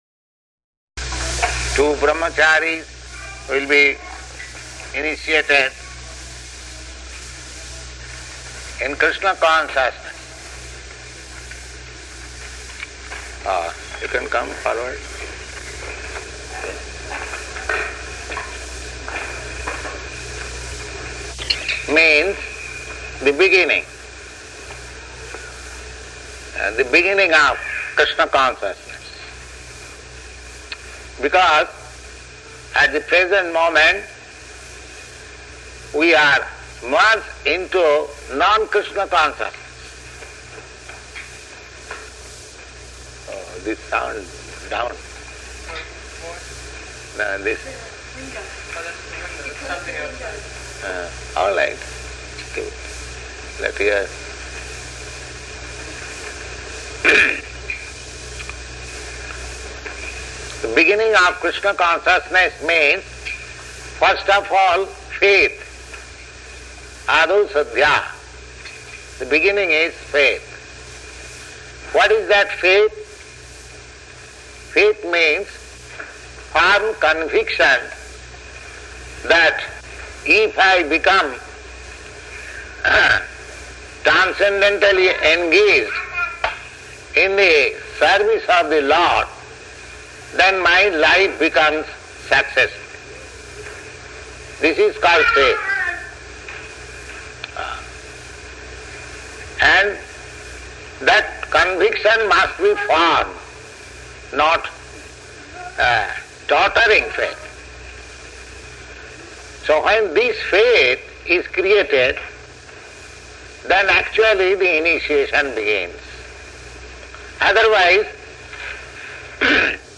-- Type: Initiation Dated: July 29th 1968 Location: Montreal Audio file